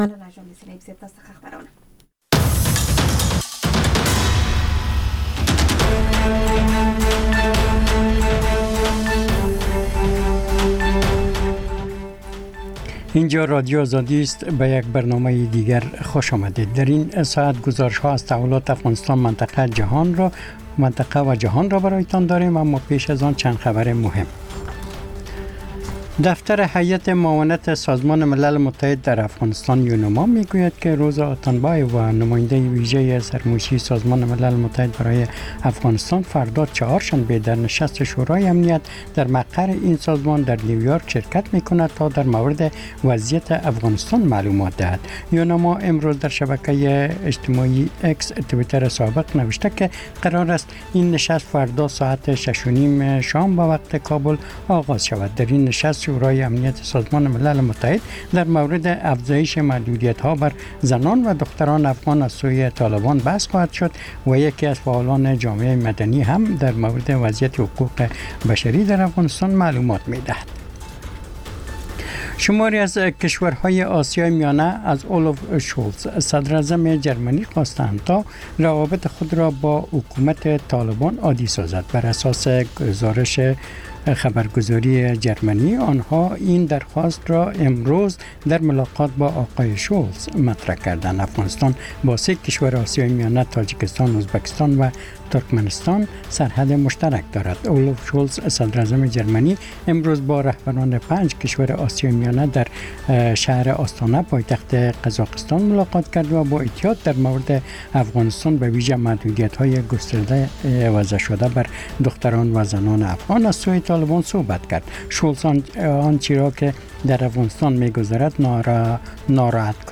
مجله خبری شامگاهی